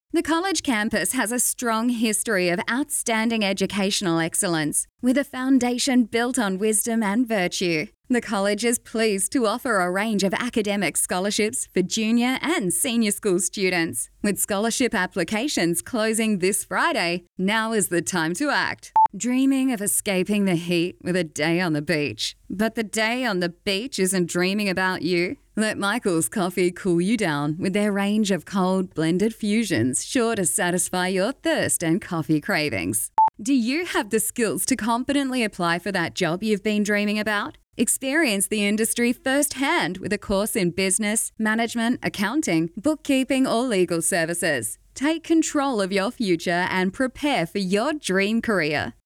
and has been described as well spoken with a clear voice that cuts through.
• Hip Young Cool
• Upbeat